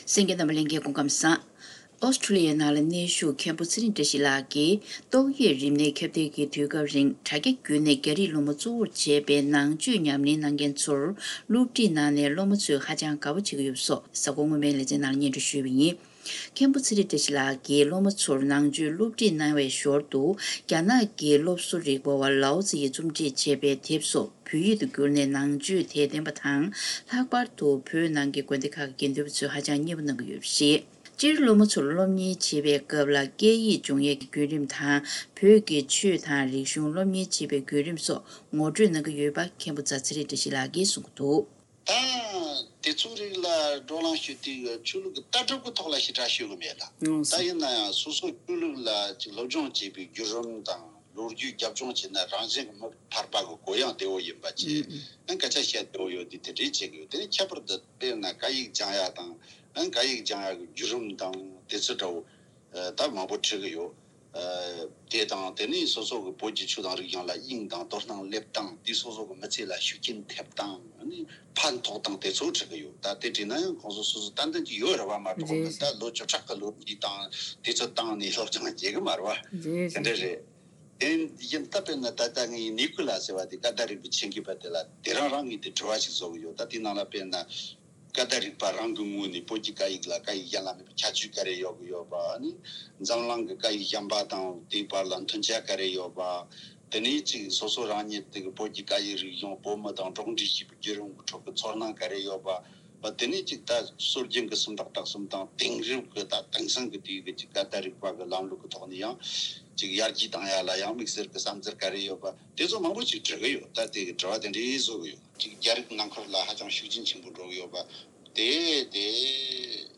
དམིགས་བསལ་བཅར་དྲིའི་ལེ་ཚན་ནང་།